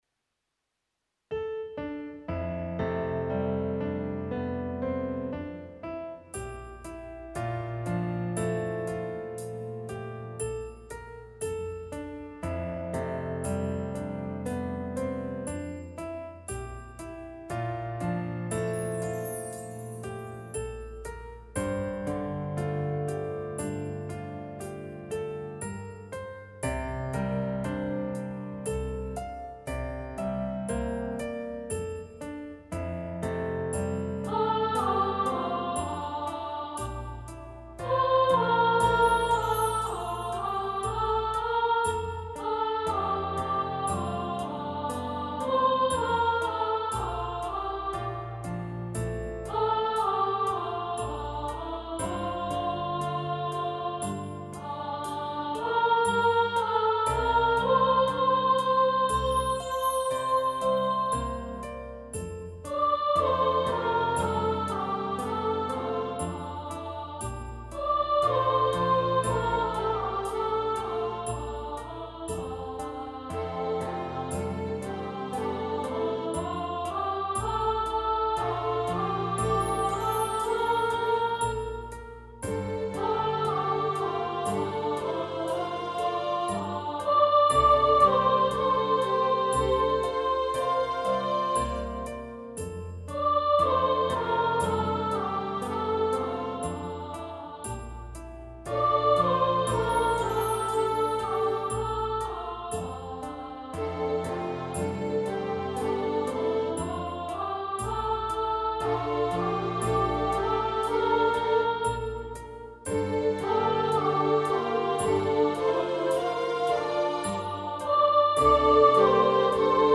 Theater für Musik.
DEMOS - 75% des Originaltempos